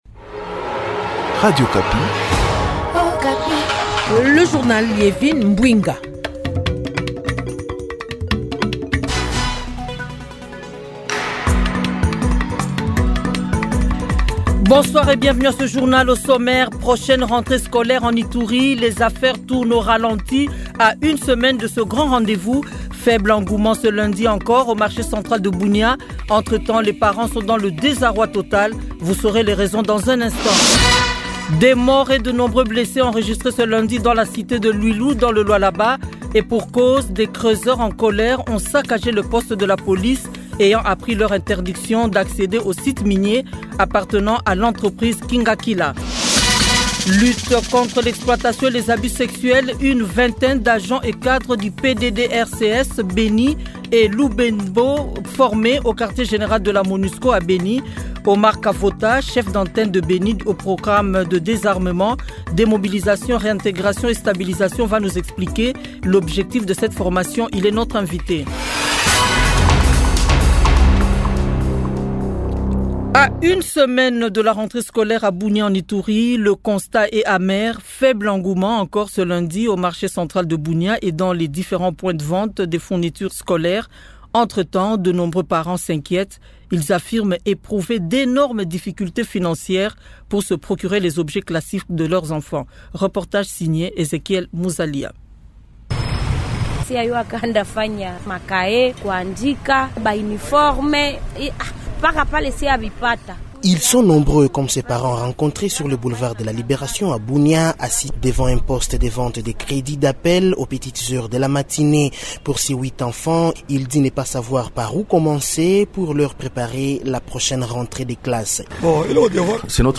Journal Francais 15 heures